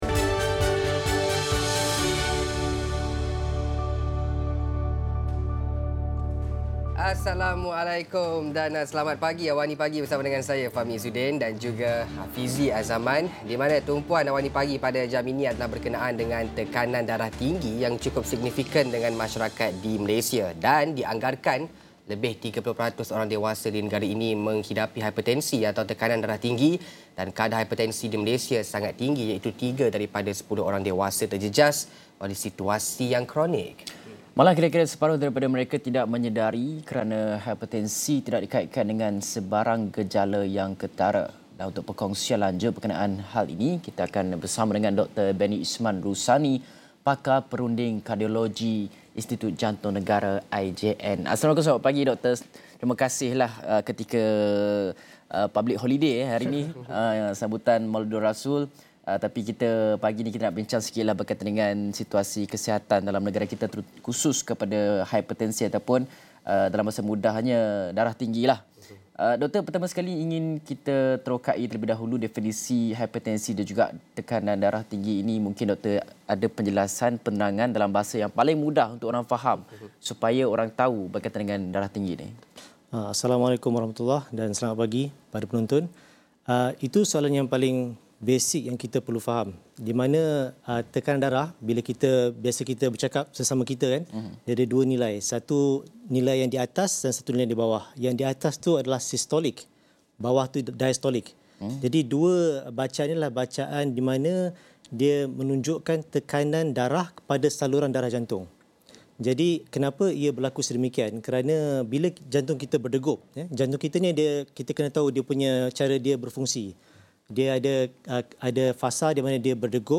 Diskusi 7.30 pagi